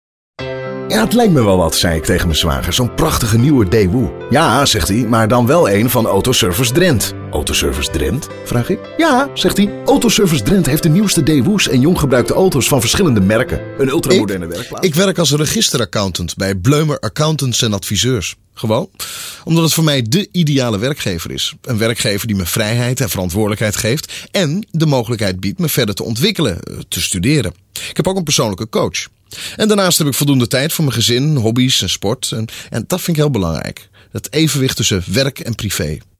Sprecher niederländisch, holländisch für Werbung, Imagefilme, Zeichentrick, DVD-Spiele etc.
Kein Dialekt
Sprechprobe: eLearning (Muttersprache):
Dutch voice over for commercials, corporate, cartoons, DVD games, toys, amusement parks, voice-respons, tv programms and so on